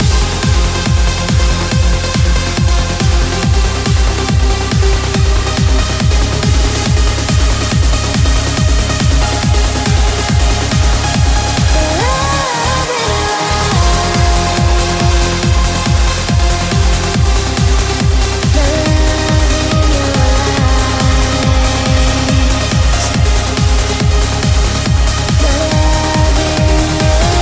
Genere: uplifting trance, (preascolto a 140bpm)